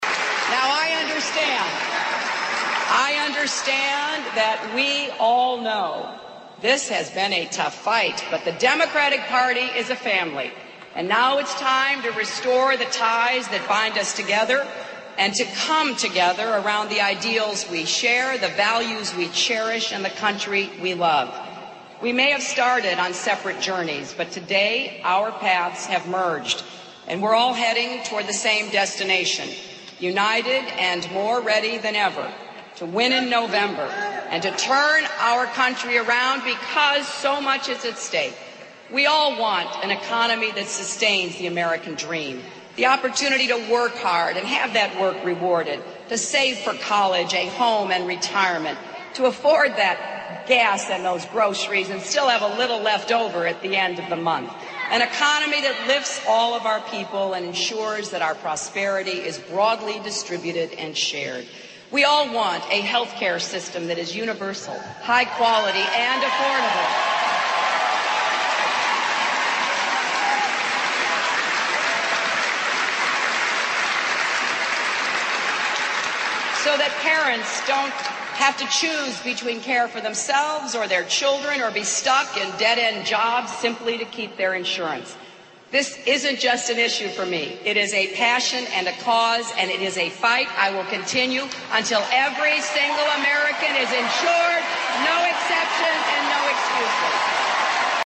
名人励志英语演讲 第72期:我放弃了 但我会继续战斗(6) 听力文件下载—在线英语听力室